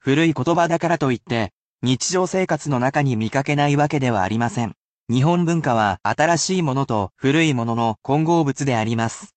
(basic polite)